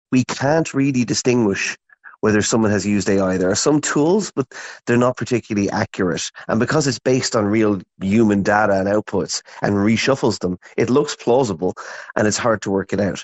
Disinformation expert and author